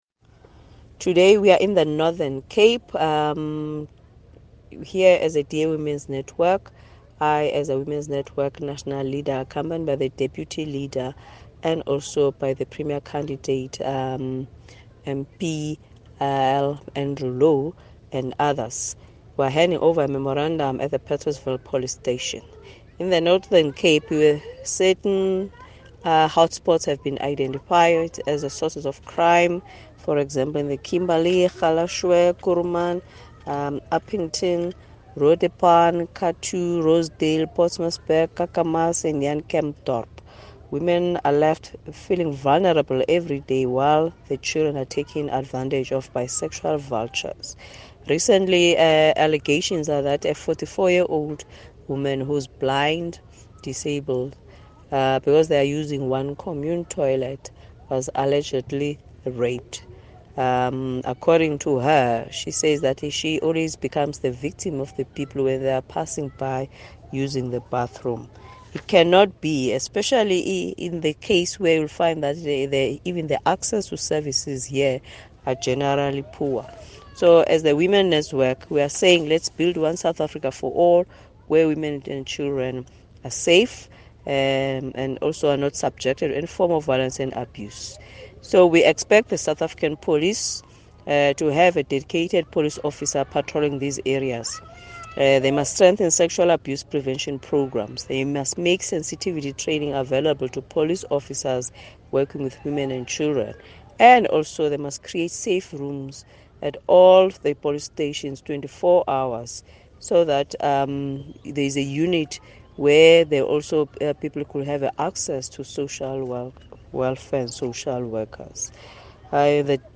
soundbite by DA Team One SA Spokesperson on Women, Dr Nomafrench Mbombo.